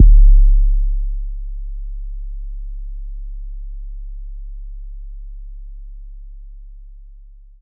Southside 808 (23).wav